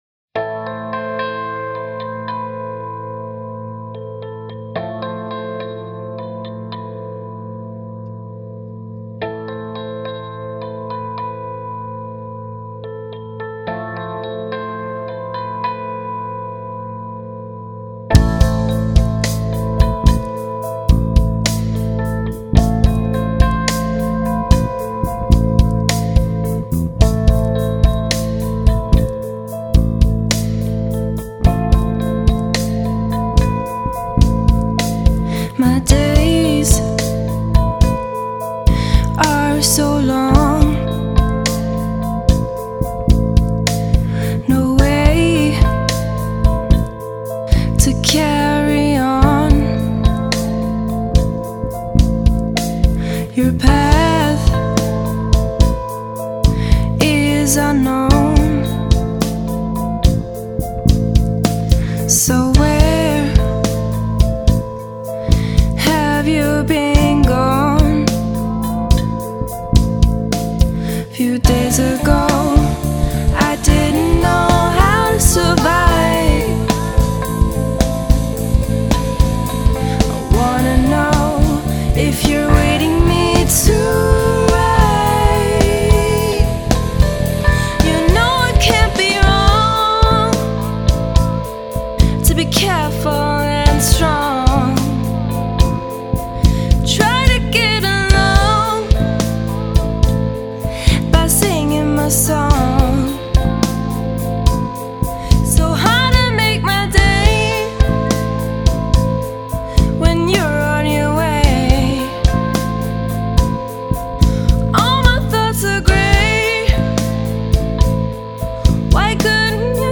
Rock/Pop-Band